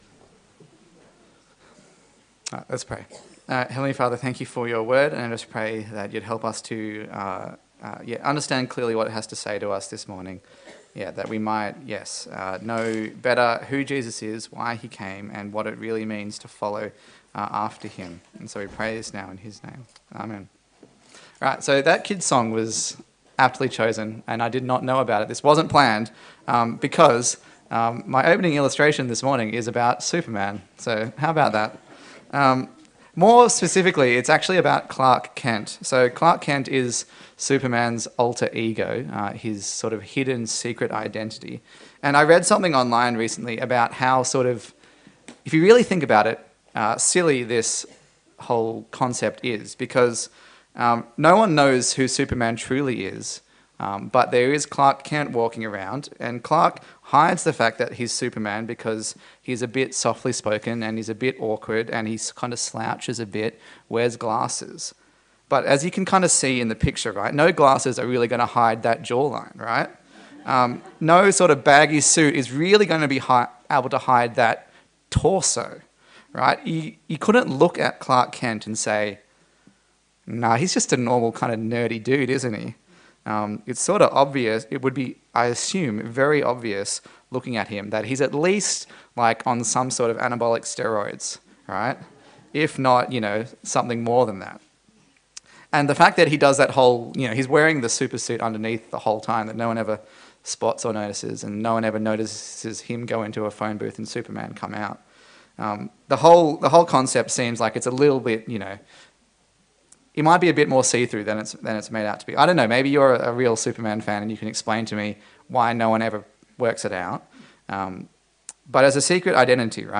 A sermon in the series on the Gospel of Luke
Passage: Luke 9:18-36 Service Type: Sunday Service